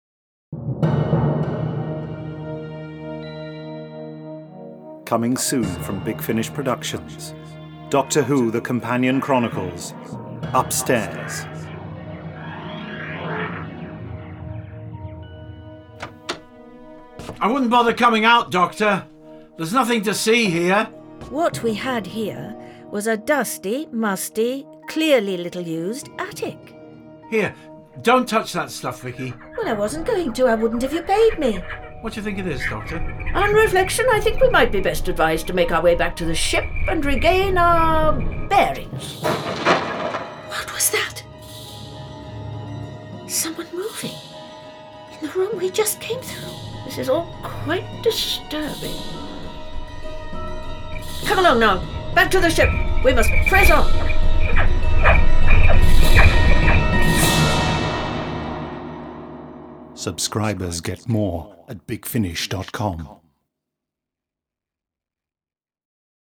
Starring Maureen O'Brien Peter Purves